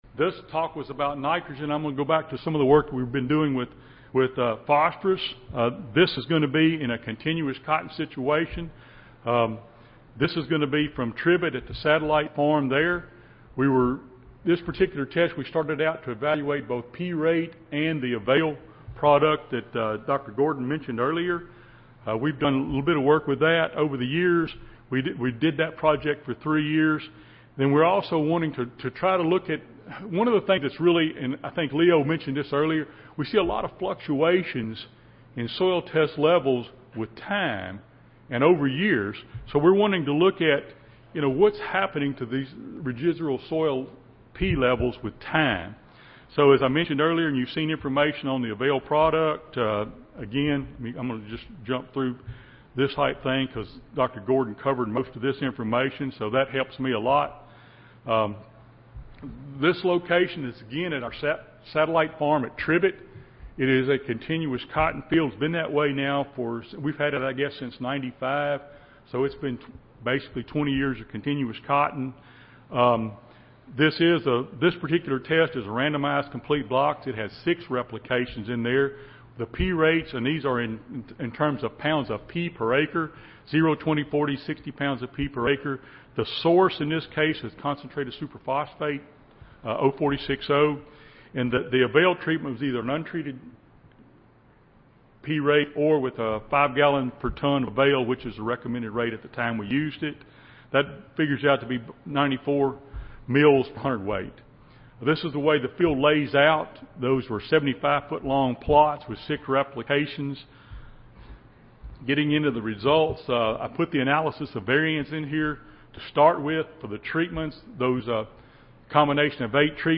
Mississippi State University Audio File Recorded Presentation Extractable soil phosphorus (P) can vary over time and is influenced by the crop being grown and the subsequent yield.